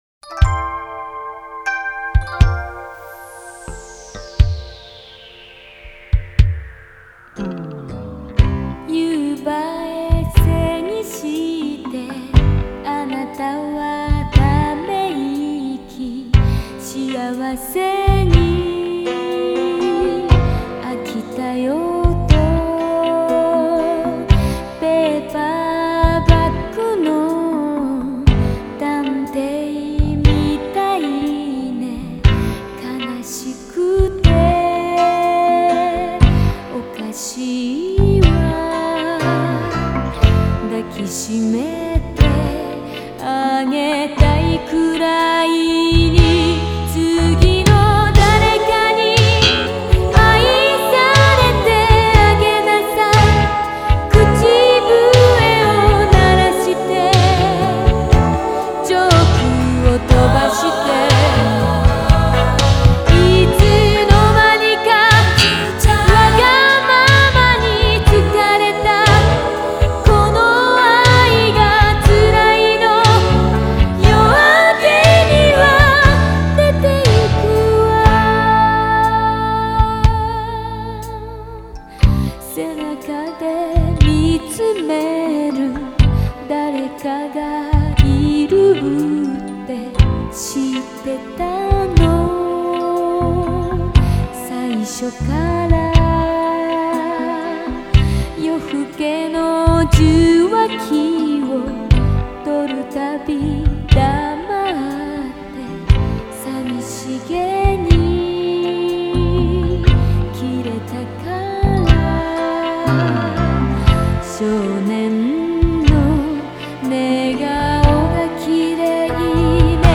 Обладает красивым и проникновенным высоким голосом.
Жанр: J-Pop, Ballad